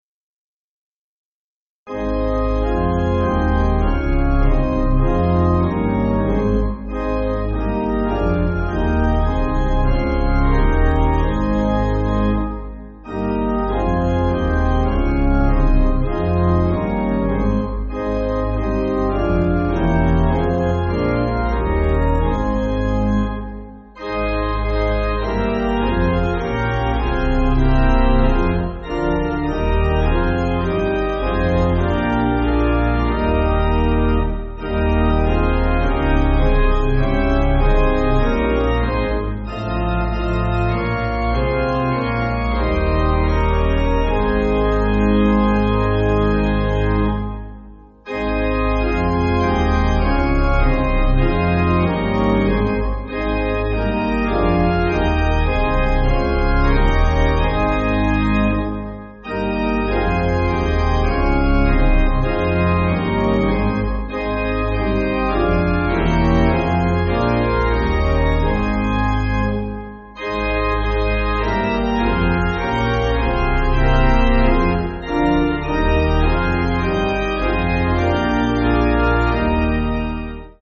Organ
(CM)   2/Ab